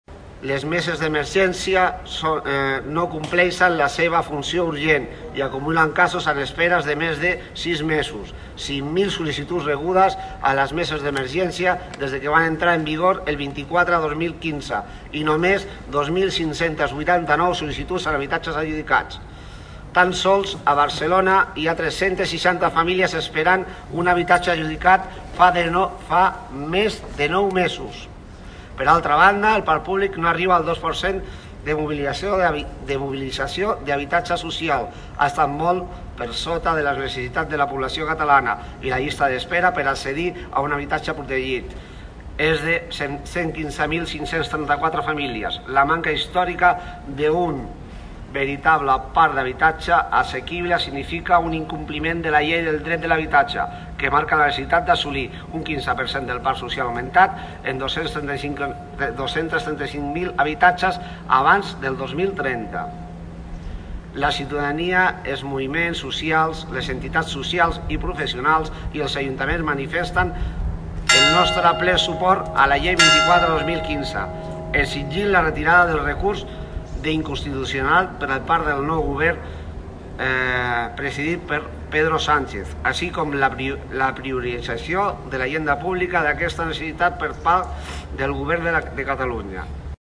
La PAH Tordera s’ha concentrat aquest matí a la plaça de l’Ajuntament per exigir la recuperació de la Llei catalana contra els desnonaments i els talls de subministraments.